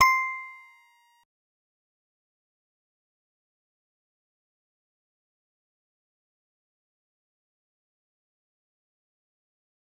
G_Musicbox-C7-mf.wav